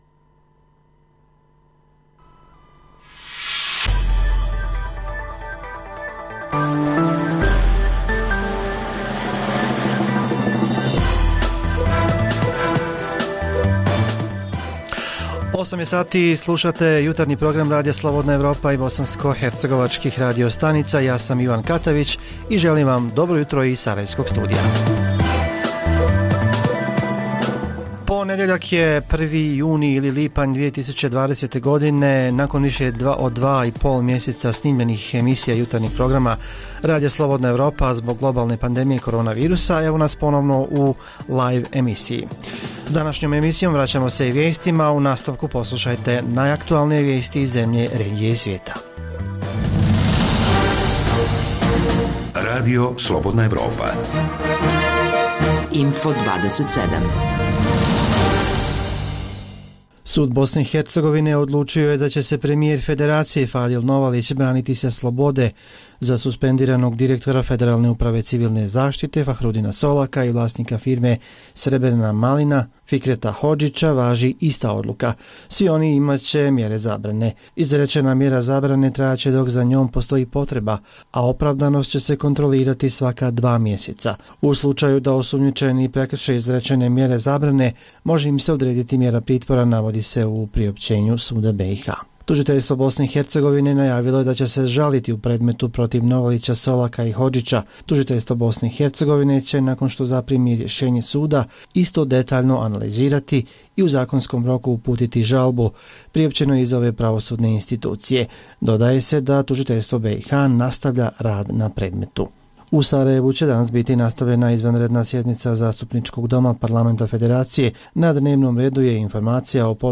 Zbog globalne pandemije ova emisija je unaprijed snimljena i nije direktan prenos. Poslušajte neke od zanimljivih priča iz raznih krajeva Bosne i Hercegovine.